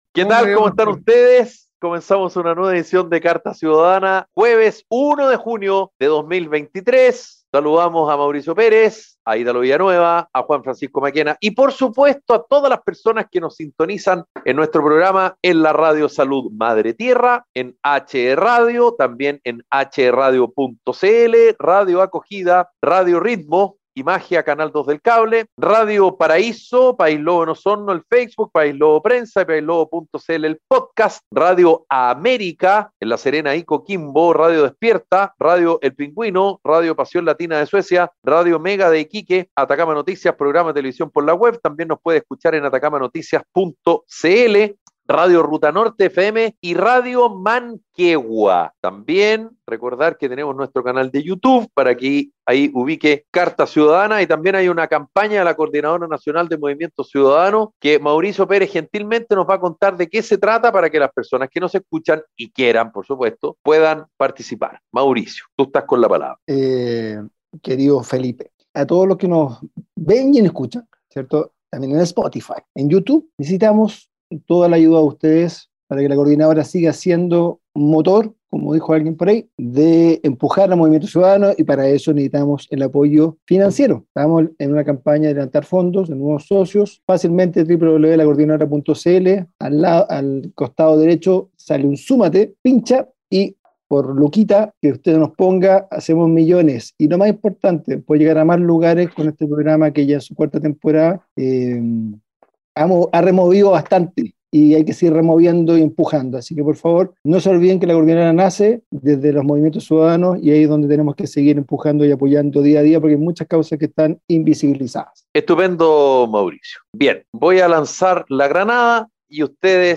🎙 Carta Ciudadana es un programa radial de conversación y análisis sobre la actualidad nacional e internacional